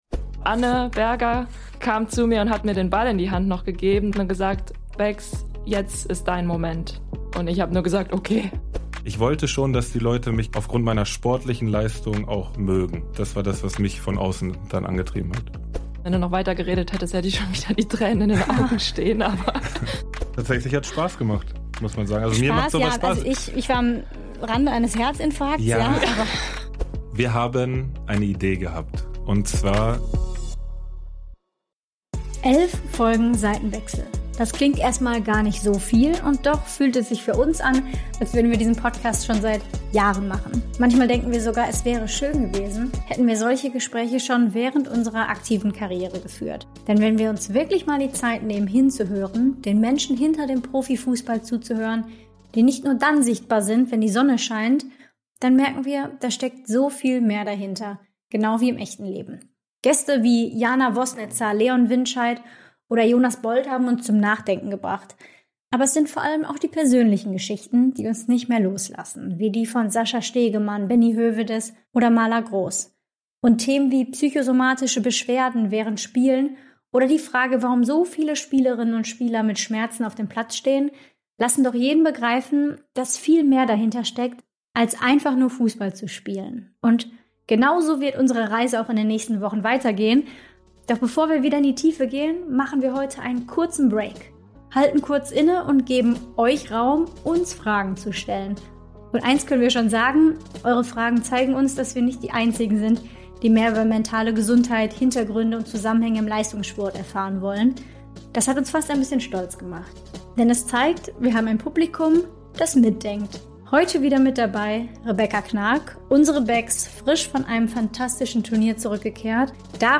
Studio & Postproduktion: Foundation Room Studio